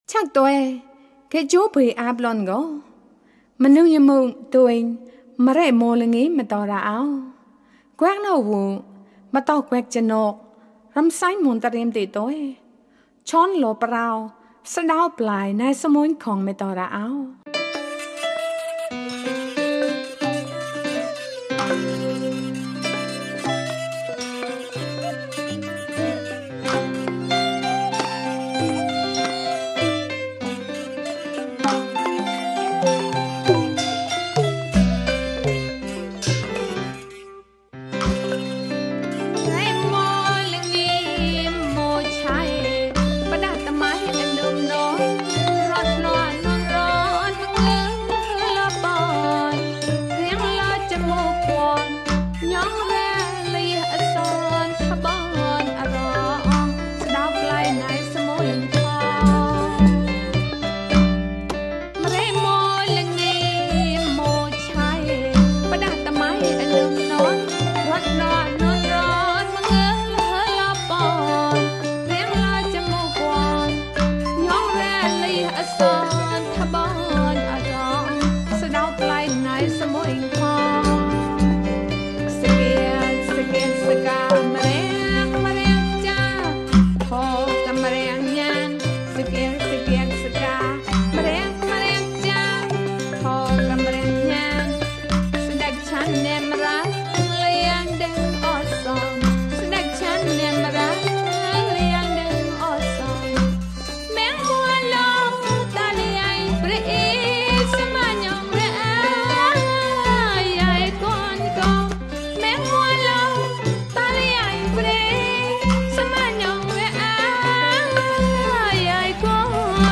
Now listen to another song: this time the singer is female.